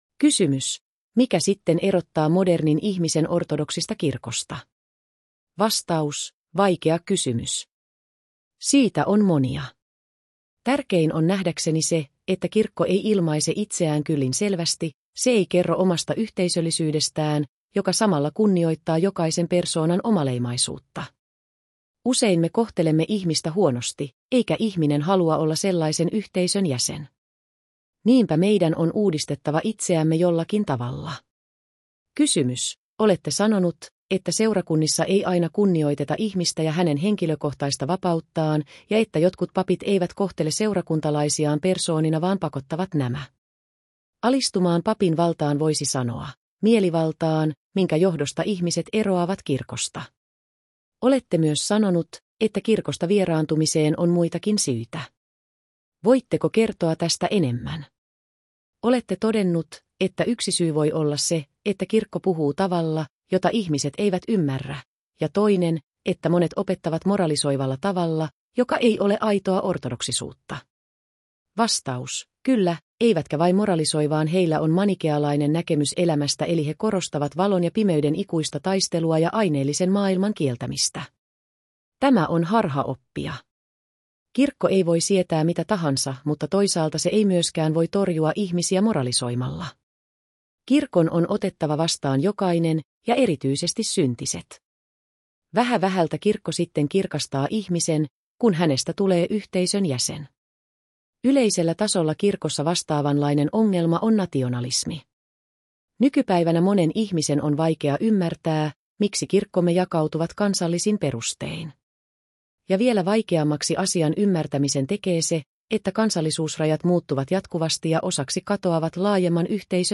Mp. Johannes Zizioulasin haastattelu osa 3